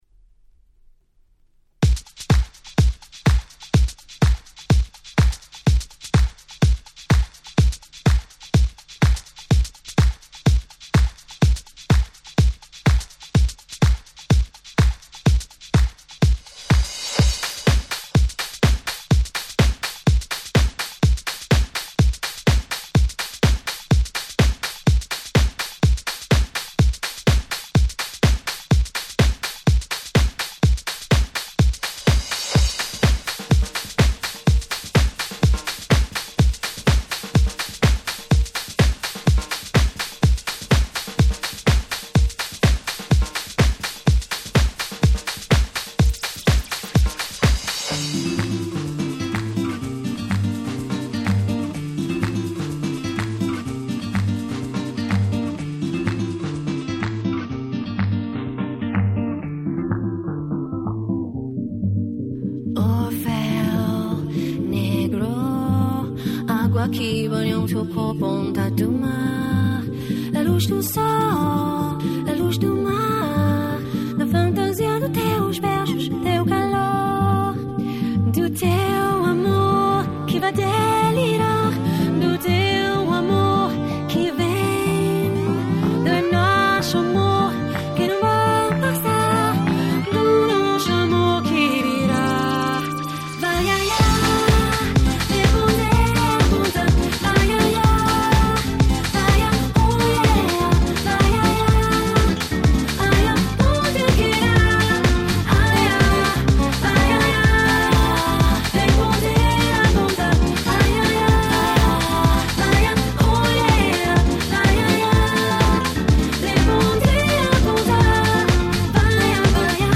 ※視聴ファイルは別の盤から録音してございます。
01’ Nice Bossa R&B !!
晴れた日のビーチで聞いたら最高！な感じの素晴らしいBossa R&B !!